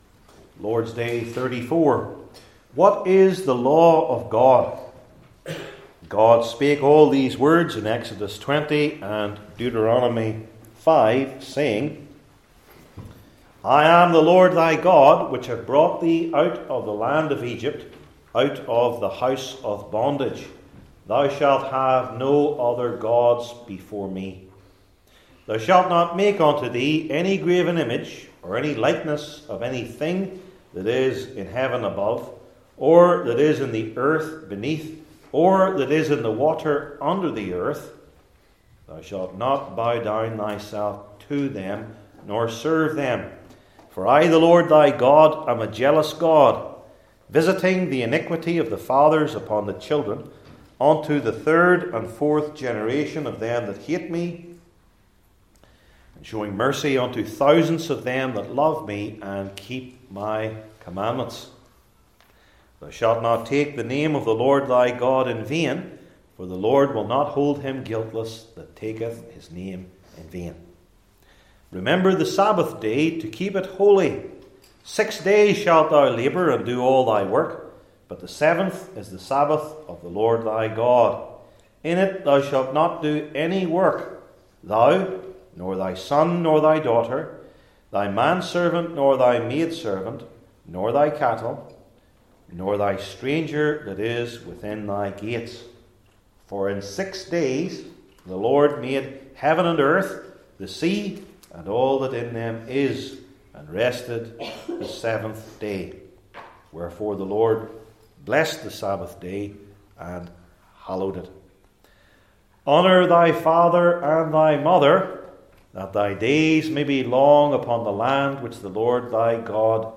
Service Type: Heidelberg Catechism Sermons